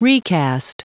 Prononciation du mot recast en anglais (fichier audio)
Prononciation du mot : recast